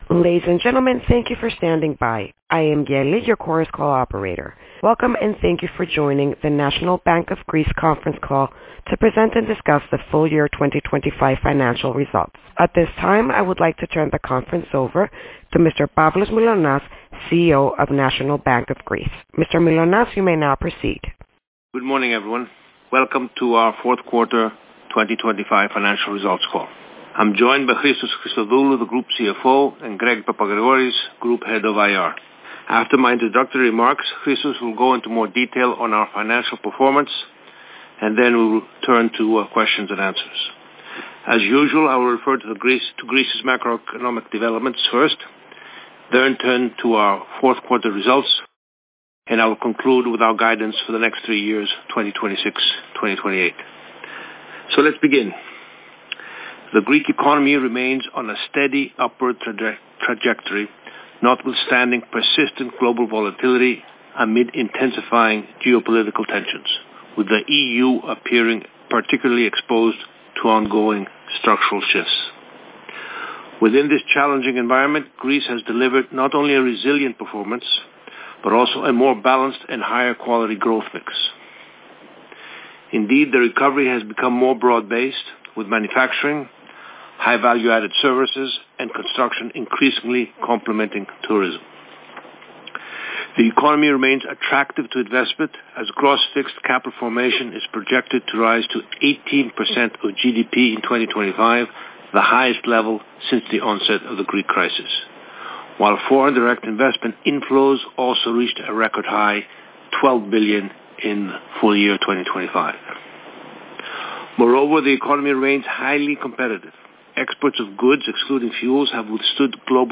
Conference Call 4Q25 Results